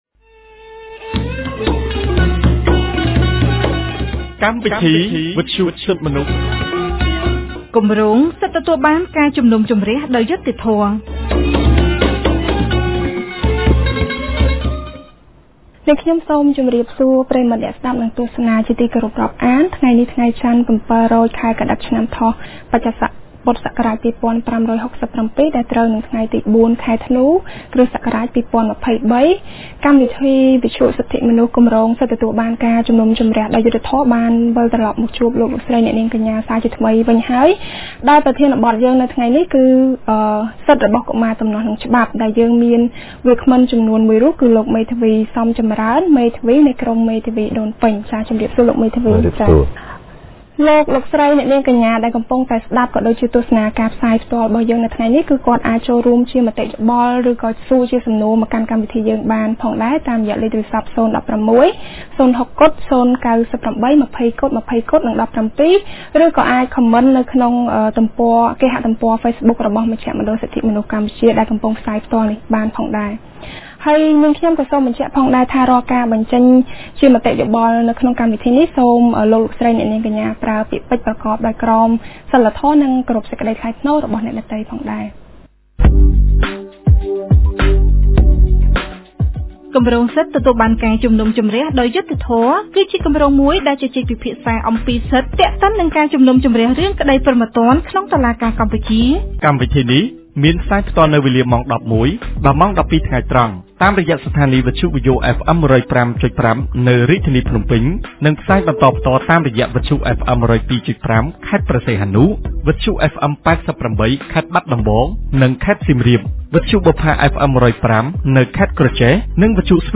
On Monday 04th December 2023, CCHR’s Fair Trial Rights Project (FTRP) held a radio program with a topic on the Right of Children in Conflict with the law.